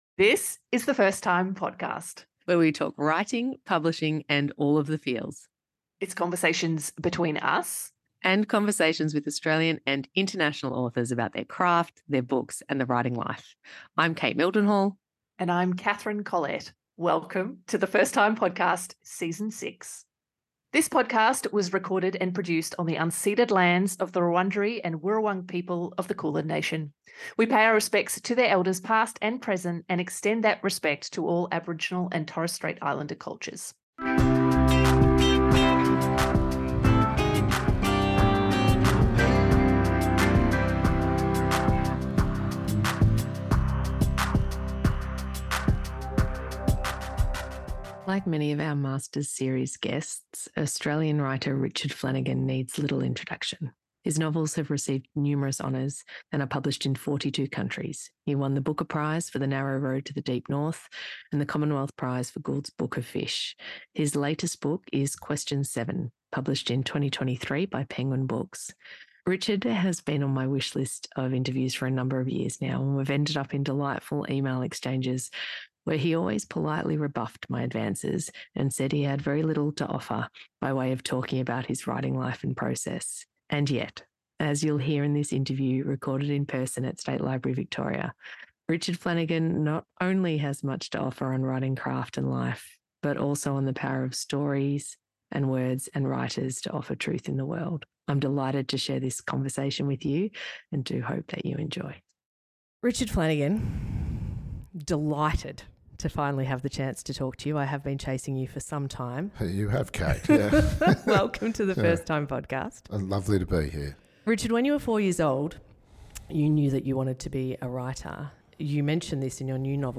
She spoke to Richard Flanagan at the end of his most recent tour, in person at State Library Victoria.